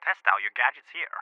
test out your gadgets here.wav